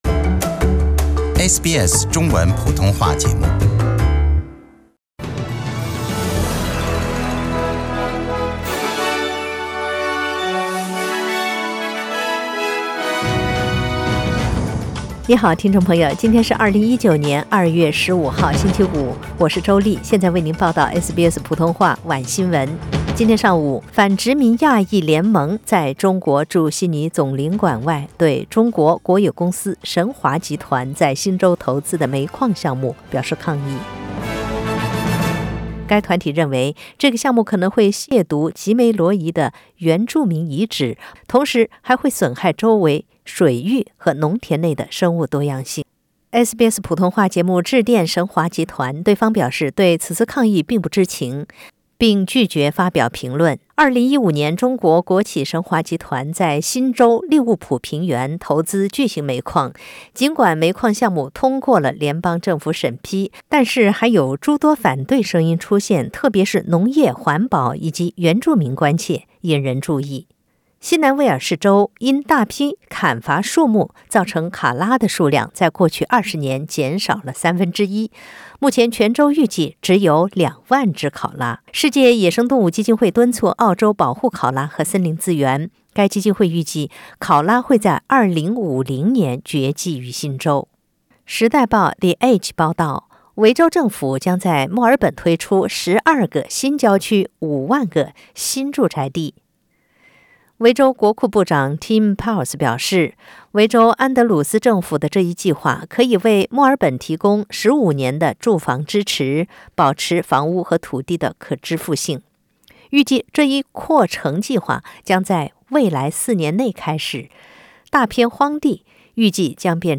SBS 晚新闻 （2月15日）